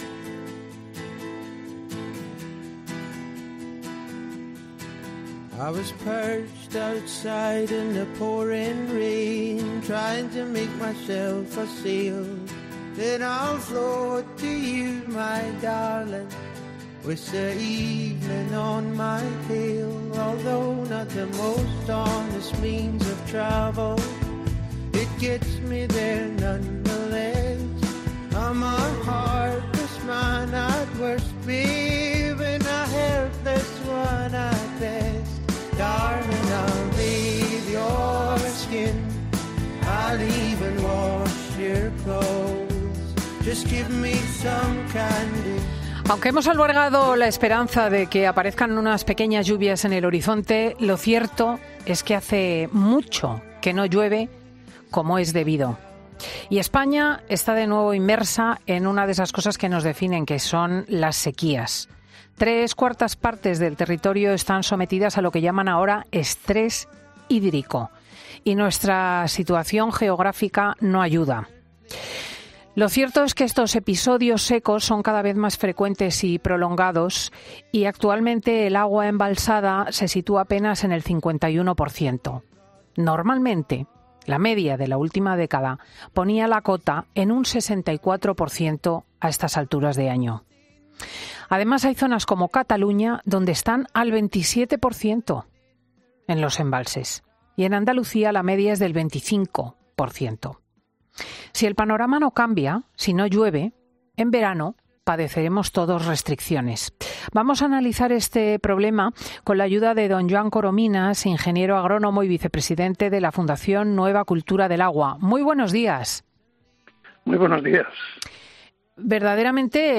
En Fin de Semana queremos saber cuáles serán esas medidas a las que nos enfrentemos y, para ello, hablamos con